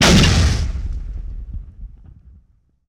punch3.wav